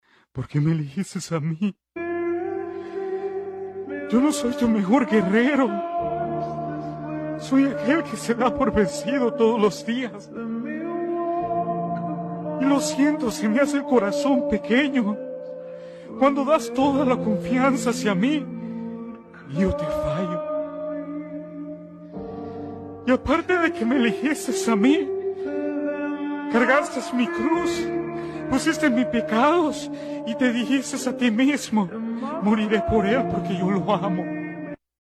Humano que se dirije a Dios , llorando preguntandole por que me elegiste a mí,